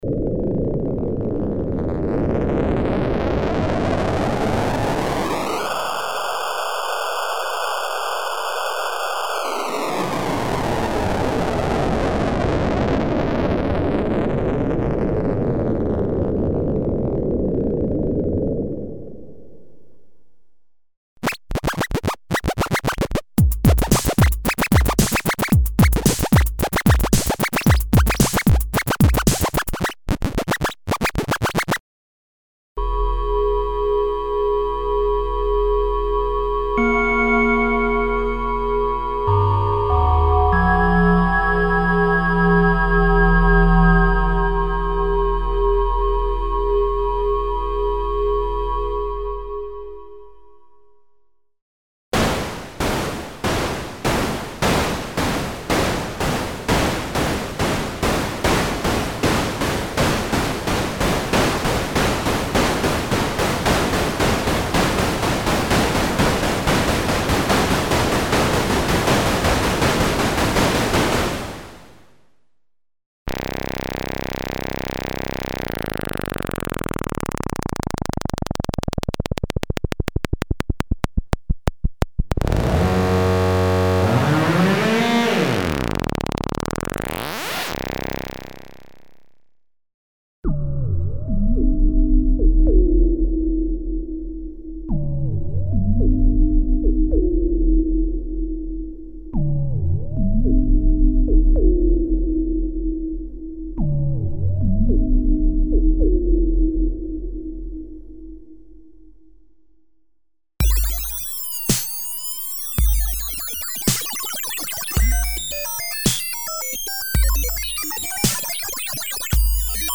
Emulations of vintage digital synthesizers - PPG, DX (FM synthesis) program variations (filtered and specially modulated noises and digital effects).
Info: All original K:Works sound programs use internal Kurzweil K2500 ROM samples exclusively, there are no external samples used.